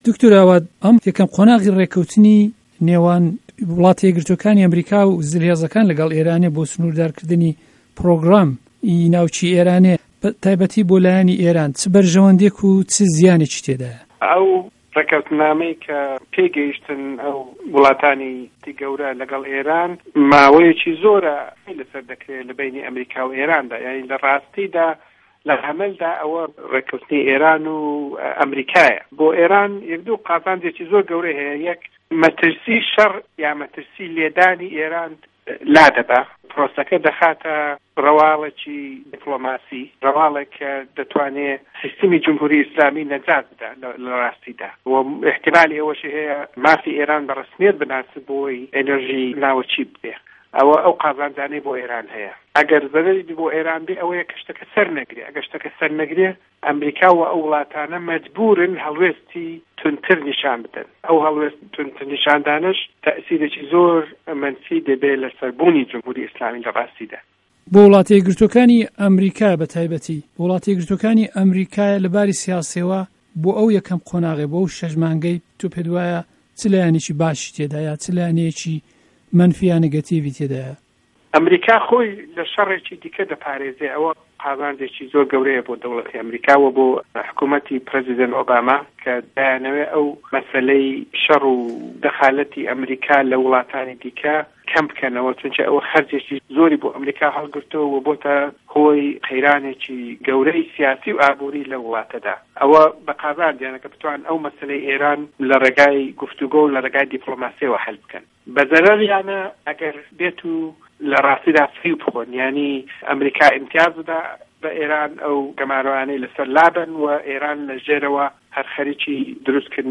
ئێران - گفتوگۆکان